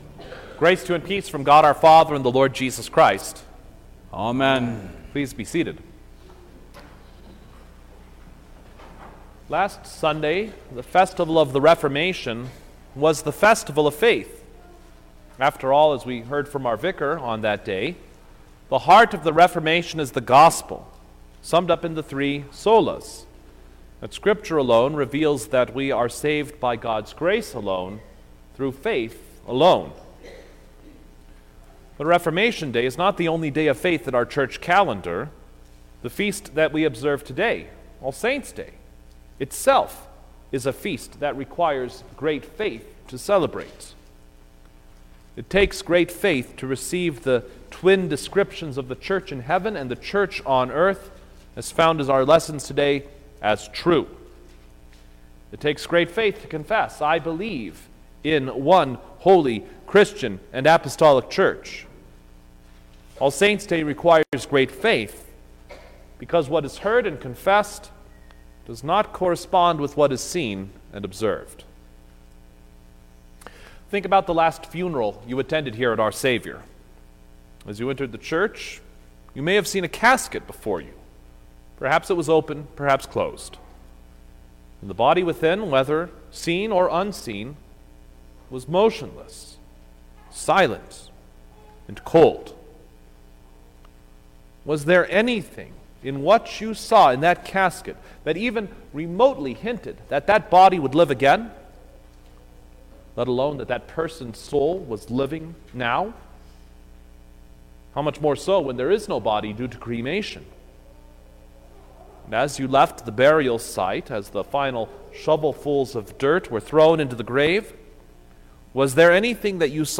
November-7_2021_All-Saints-Day_Sermon_Stereo.mp3